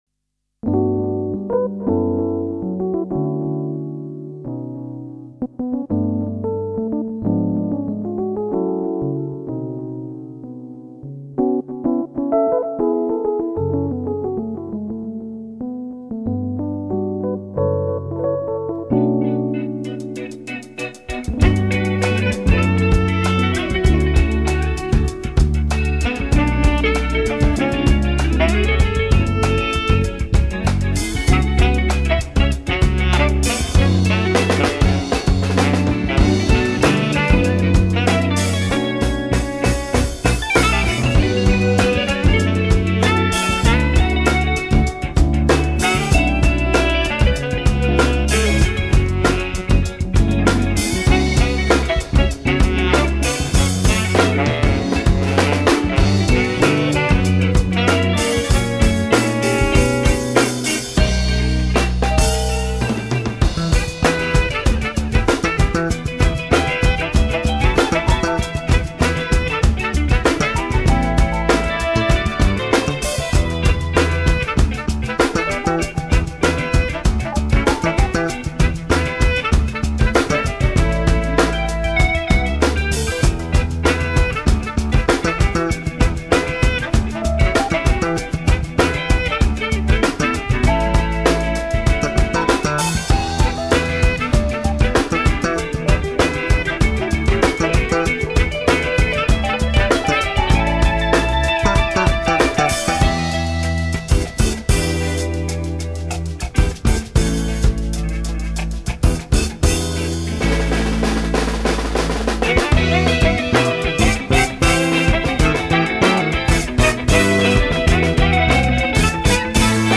im Wki-Studio in Bad Münstereifel
Trompete
Saxophon